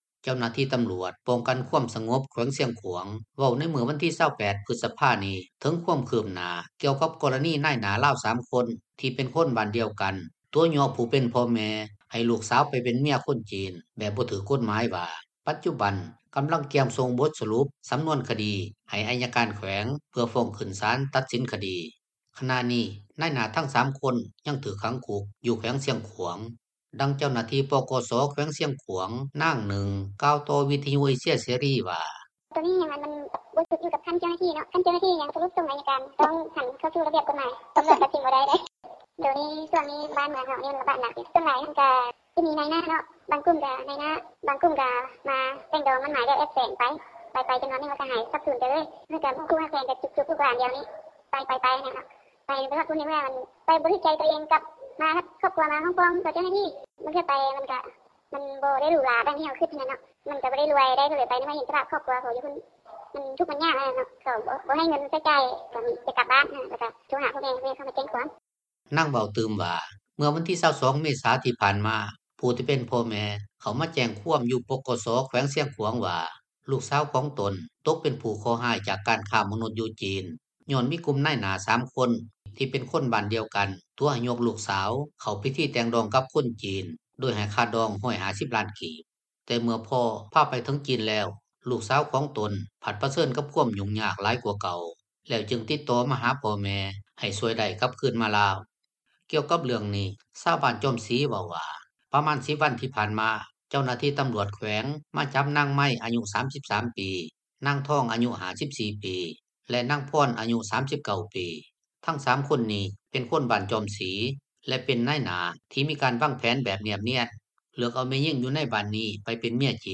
ຂະນະນີ້ ນາຍໜ້າທັງ 3 ຄົນຍັງຖືກຂັງຄຸກ ຢູ່ແຂວງຊຽງຂວາງ ດັ່ງເຈົ້າໜ້າທີ່ ປກສ ແຂວງຊຽງຂວາງ ນາງນຶ່ງ ກ່າວຕໍ່ວິທຍຸເອເຊັຽເສຣີ ວ່າ: